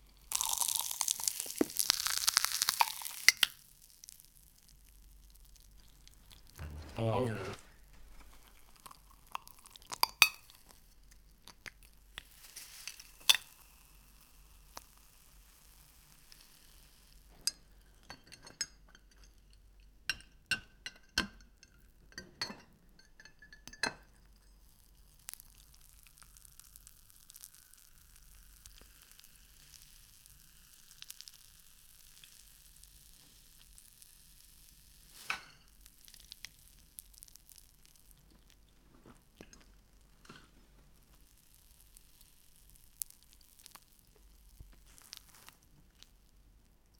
Sizzling fried meat crackle, crispy baby potatoes crunch, onion slices sharp chop. Background: veggie salad chopping/mixing, utensil clinks, man eating, distant kitchen pan sizzle. Warm, hearty meal atmosphere. Balance: 60% close-up, 40% ambient 0:47 Created Apr 13, 2025 10:58 AM
sizzling-fried-meat-crack-pwnmagk2.wav